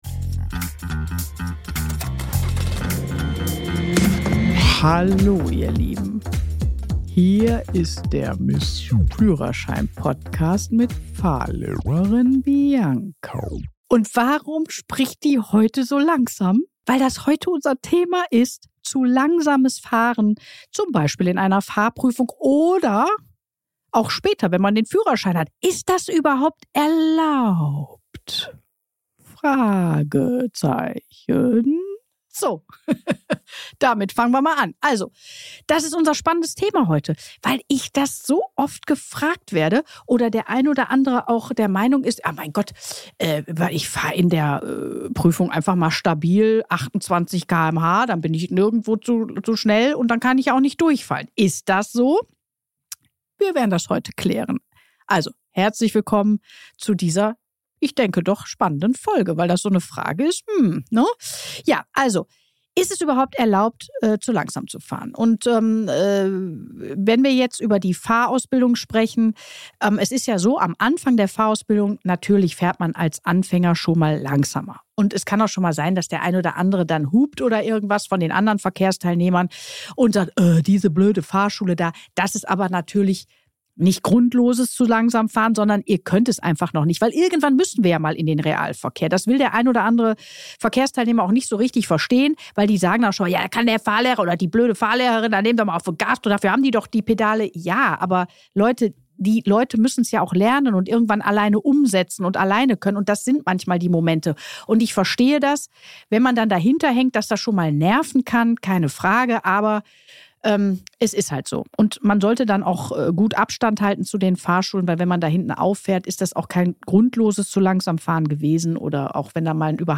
Beschreibung vor 4 Monaten In dieser Solo-Folge spreche ich über ein Thema, das in der Fahrprüfung oft unterschätzt wird: zu langsames Fahren.